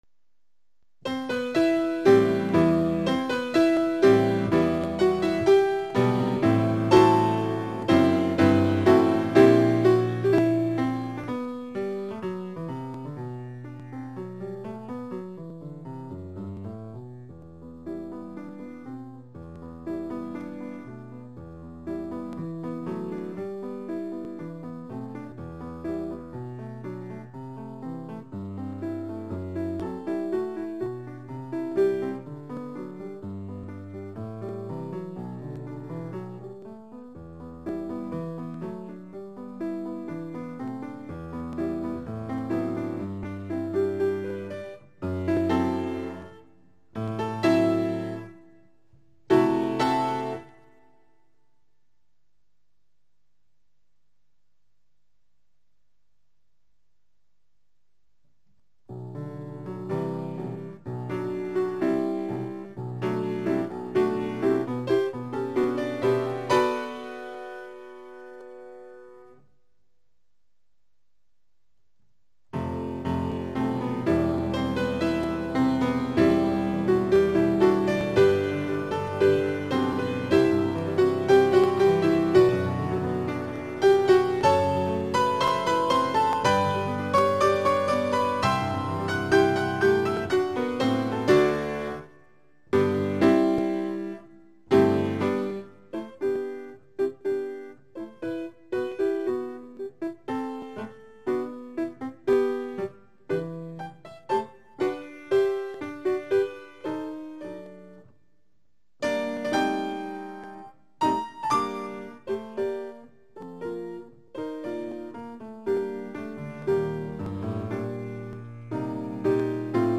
0072-科玛洛夫斯基e小调第一小提琴协奏曲1.mp3